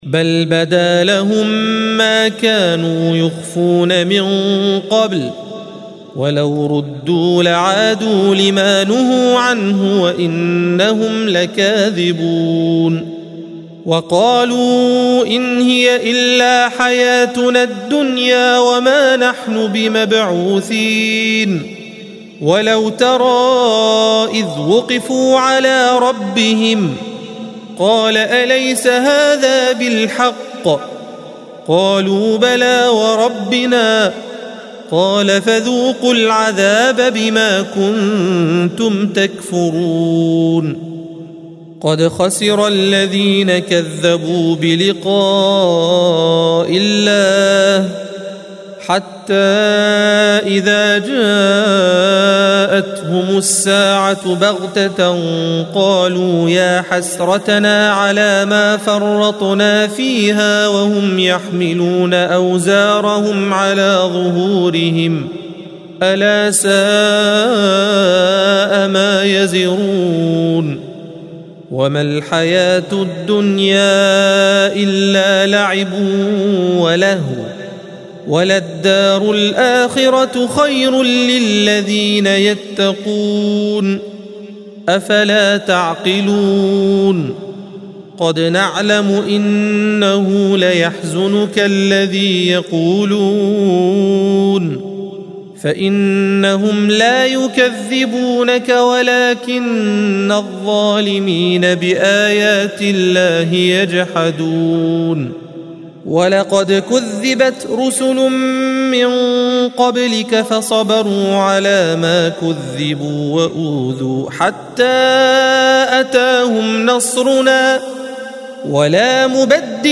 الصفحة 131 - القارئ